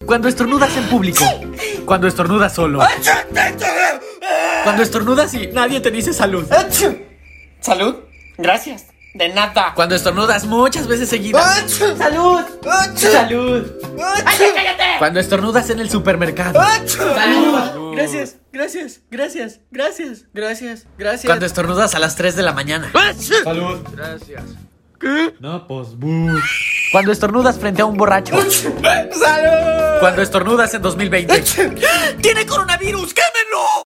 Tipos de estornudos 😂 // sound effects free download